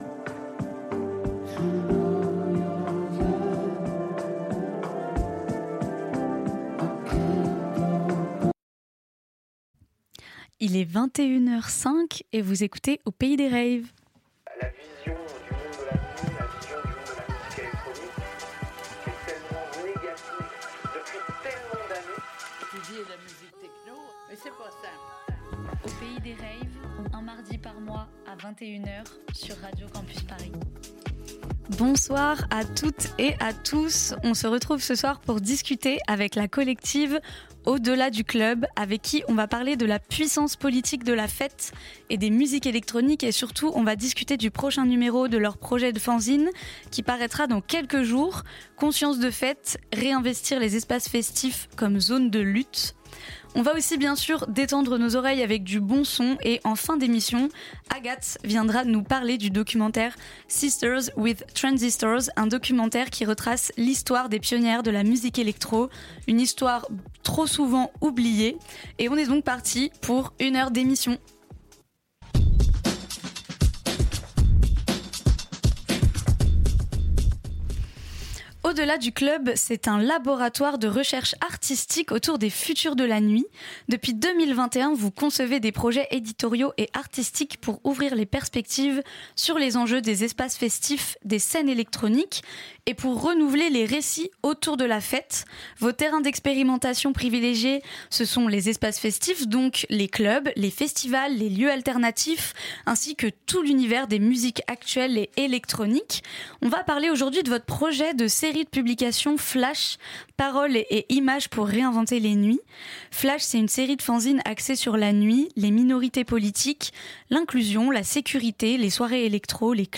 Retrouvez le cinquième épisode, en direct, de l'émission Aux Pays Des Raves !